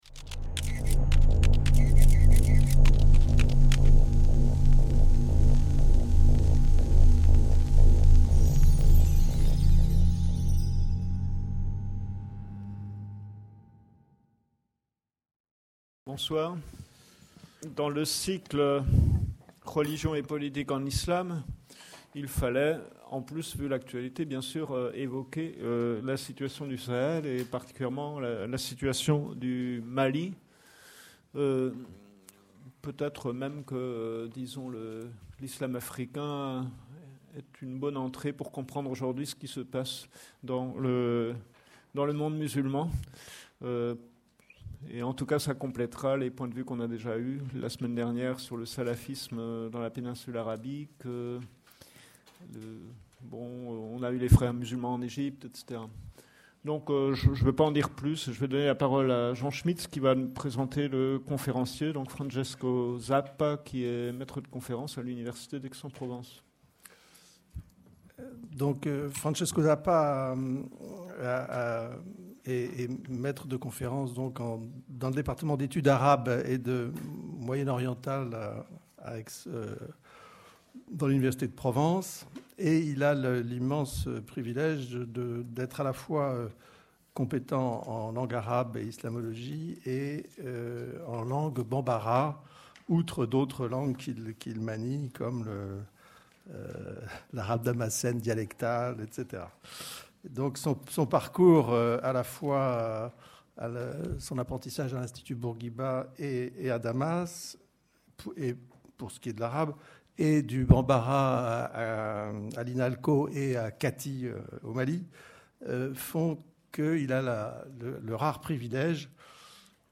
08 - Conférence